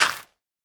Minecraft Version Minecraft Version 1.21.5 Latest Release | Latest Snapshot 1.21.5 / assets / minecraft / sounds / block / composter / fill_success2.ogg Compare With Compare With Latest Release | Latest Snapshot
fill_success2.ogg